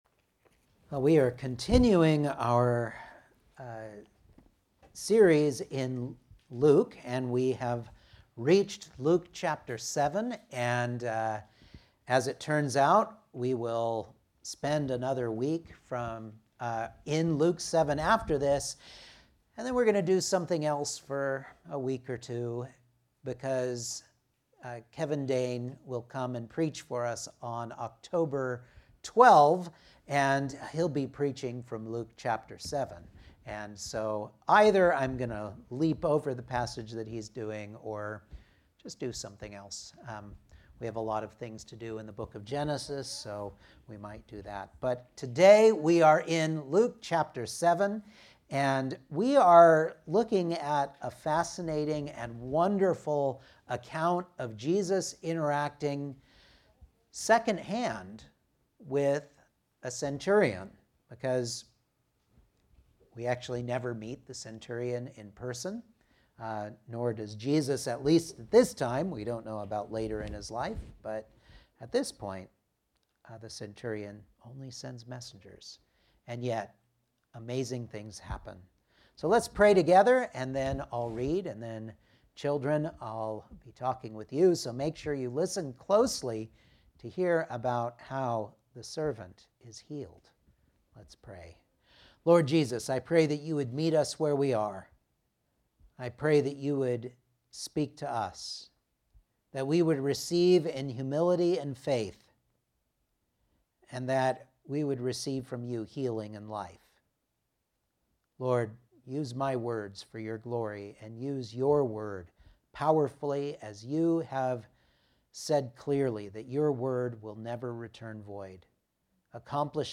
Luke 7:1-10 Service Type: Sunday Morning Outline